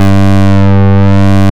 Index of /90_sSampleCDs/Trance_Explosion_Vol1/Instrument Multi-samples/Wasp Bass 1
G2_WaspBass_1.wav